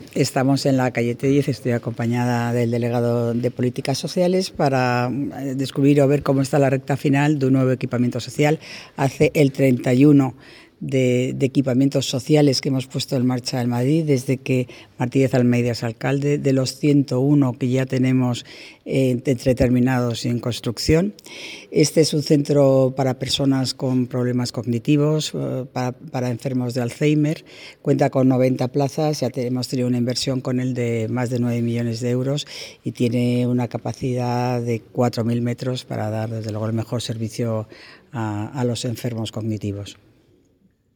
La delegada de Obras y Equipamientos, Paloma García Romero: